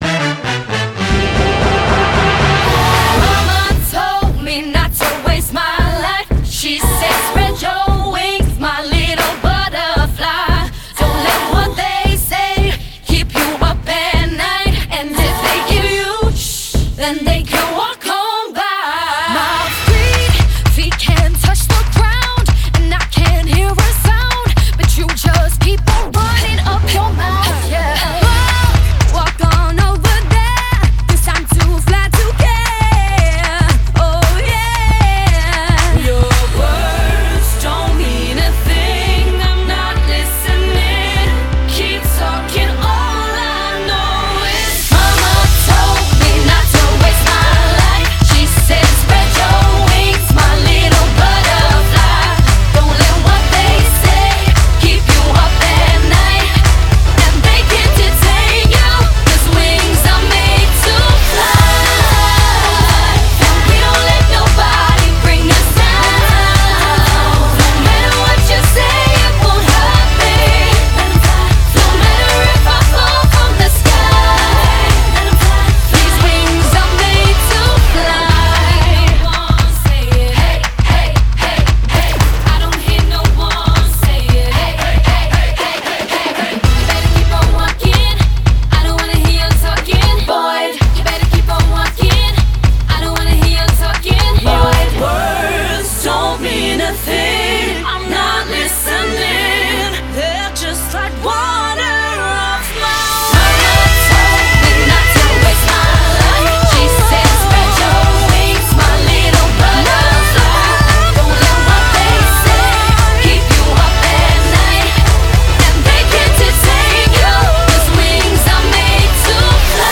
BPM115
MP3 QualityMusic Cut